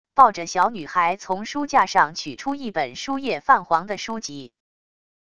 抱着小女孩从书架上取出一本书页泛黄的书籍wav音频